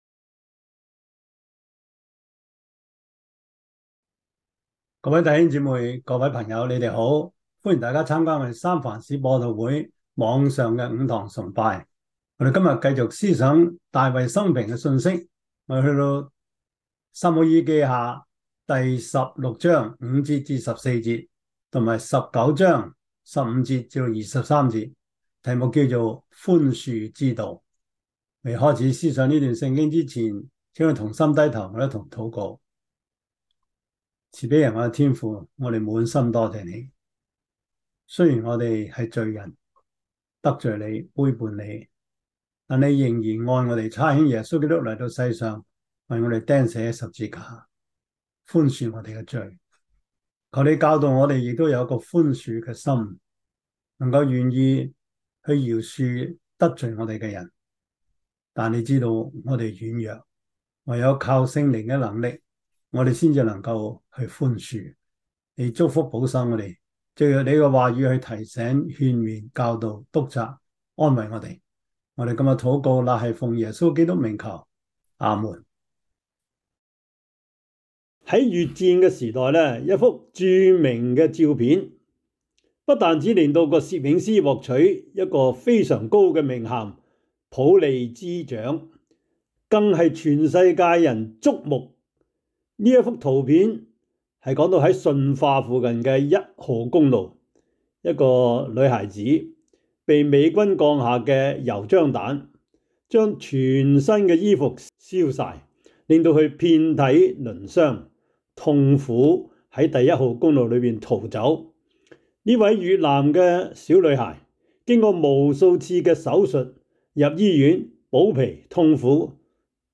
19:15-23 Service Type: 主日崇拜 撒母耳記下 16:5-14 Chinese Union Version